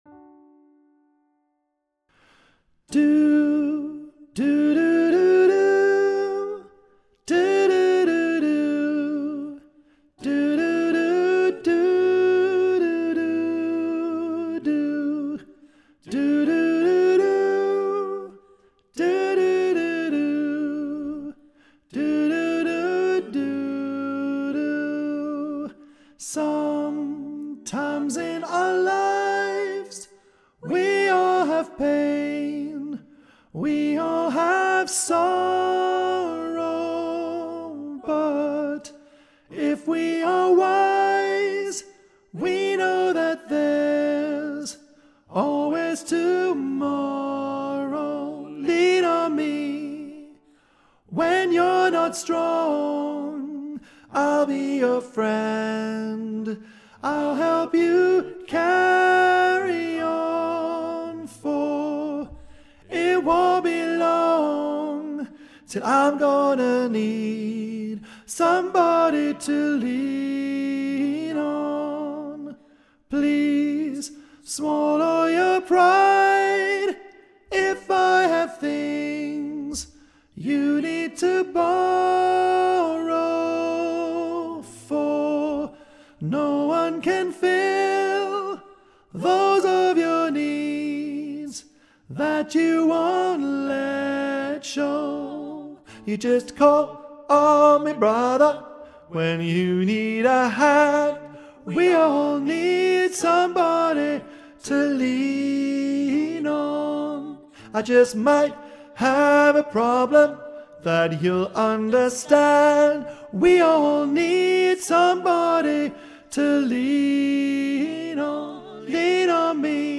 Lean on Me - Four Part Harmony — More Than Just A Choir
LEAN-ON-ME-ALTO.mp3